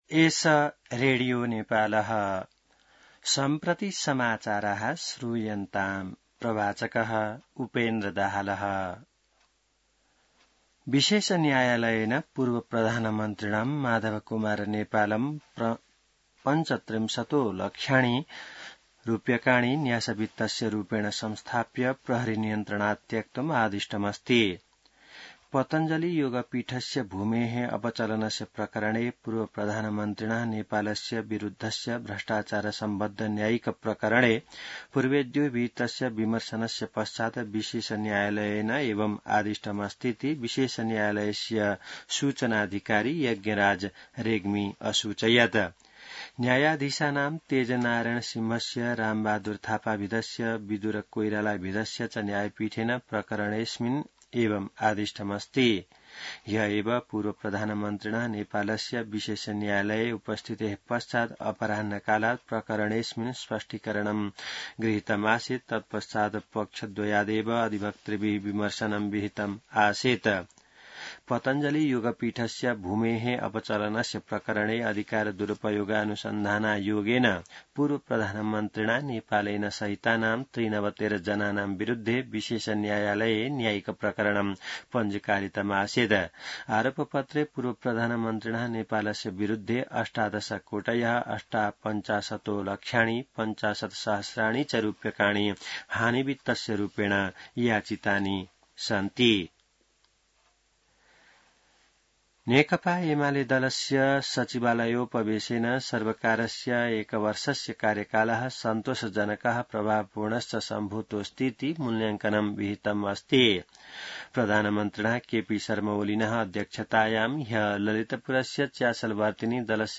संस्कृत समाचार : १२ असार , २०८२